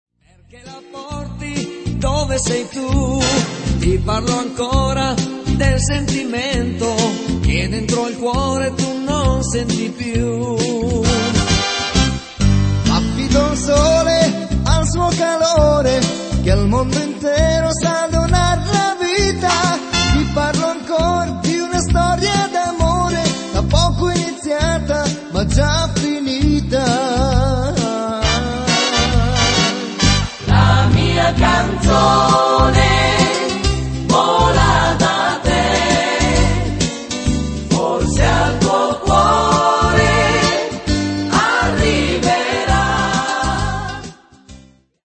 fox